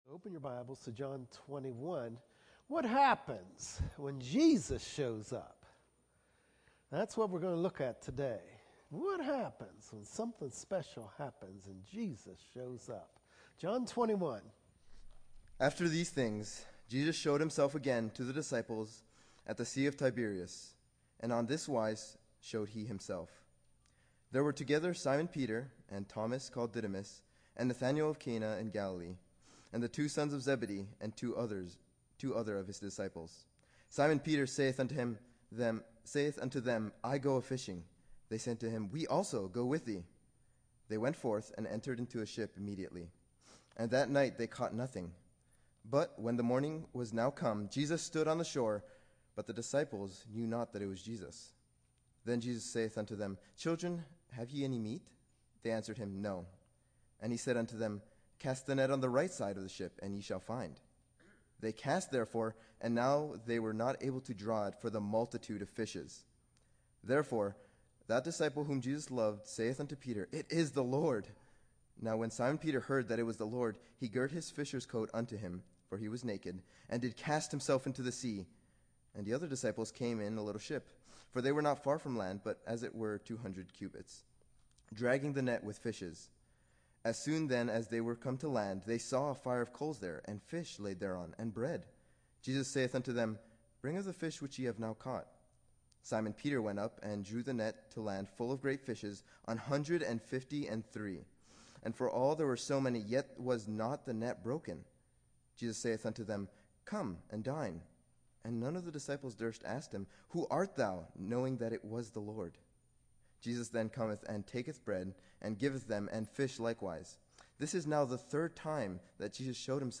Chapel Message